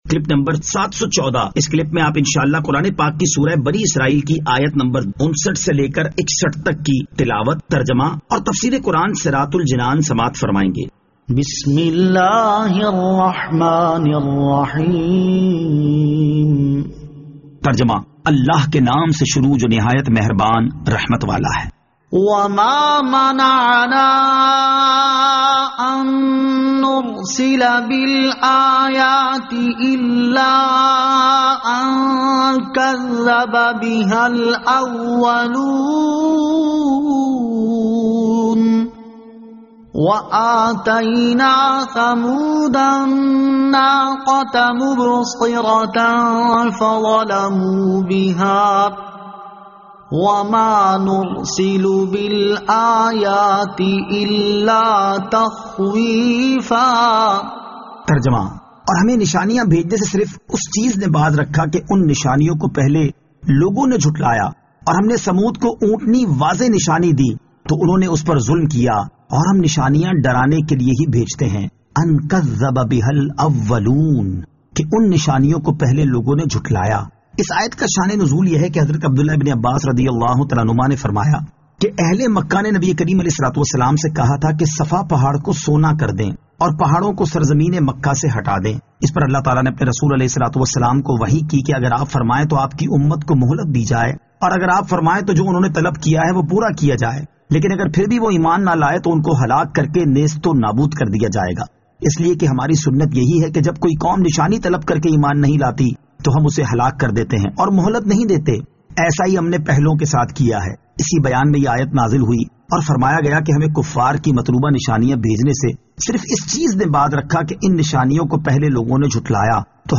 Surah Al-Isra Ayat 59 To 61 Tilawat , Tarjama , Tafseer
2021 MP3 MP4 MP4 Share سُوَّرۃُ الاسٗرَاء آیت 59 تا 61 تلاوت ، ترجمہ ، تفسیر ۔